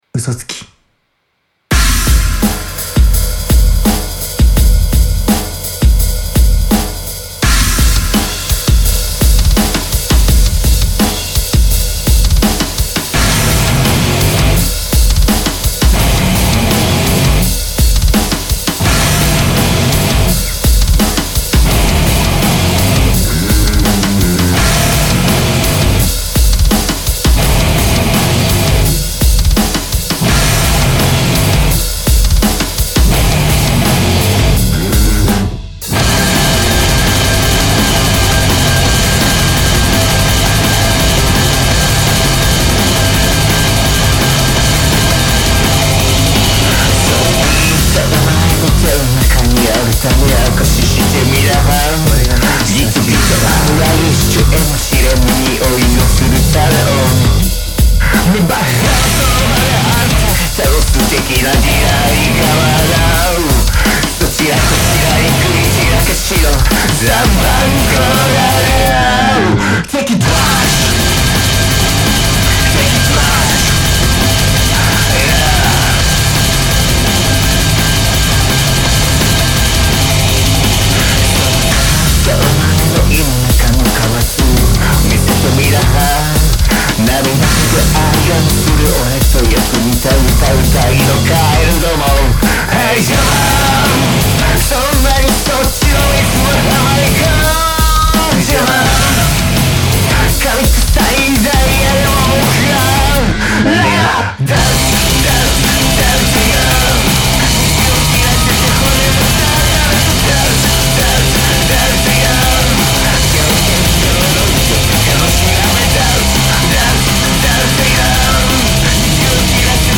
珍しくそんなにアレンジしてません。